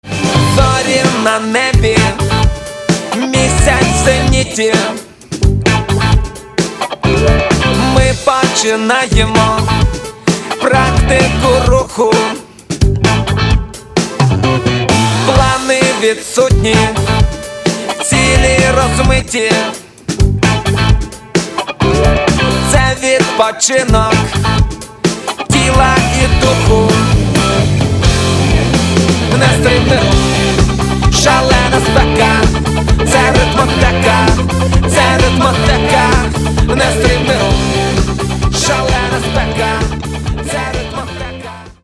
Catalogue -> Hip-Hop